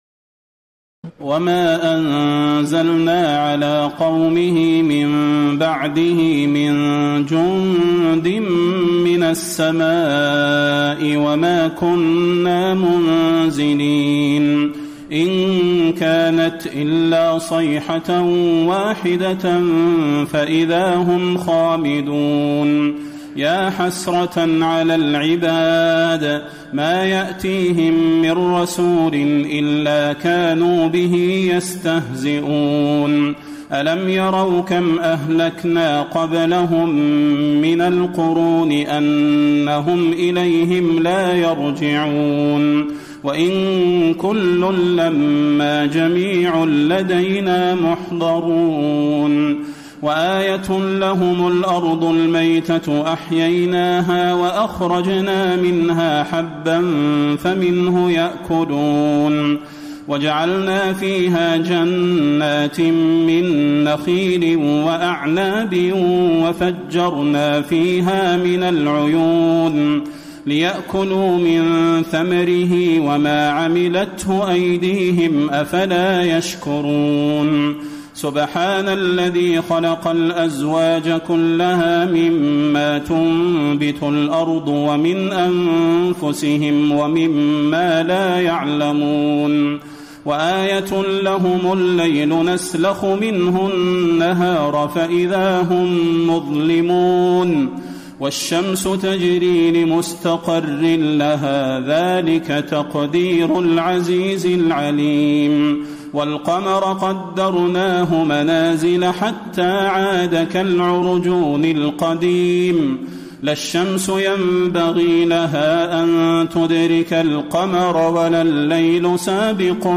تراويح ليلة 22 رمضان 1434هـ من سور يس (28-83) والصافات(1-138) Taraweeh 22 st night Ramadan 1434H from Surah Yaseen and As-Saaffaat > تراويح الحرم النبوي عام 1434 🕌 > التراويح - تلاوات الحرمين